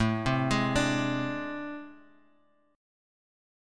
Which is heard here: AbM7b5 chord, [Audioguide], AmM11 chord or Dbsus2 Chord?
AmM11 chord